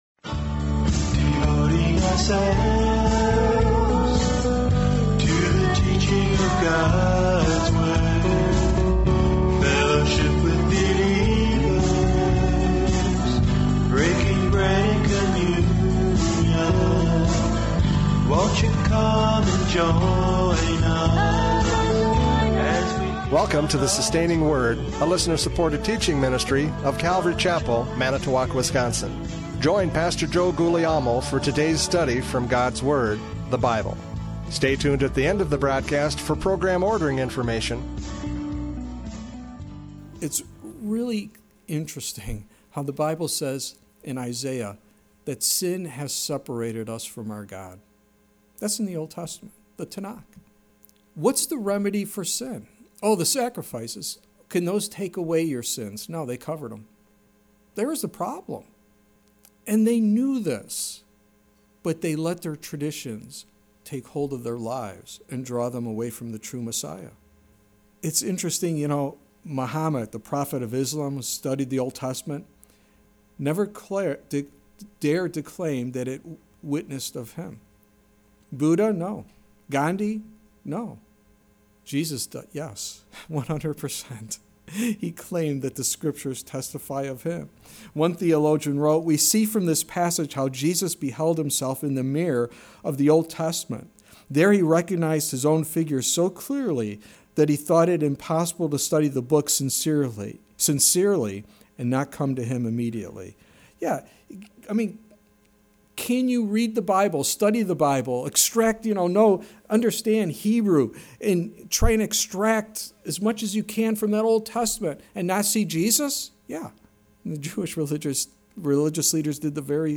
John 5:39-47 Service Type: Radio Programs « John 5:39-47 Testimony of Scripture!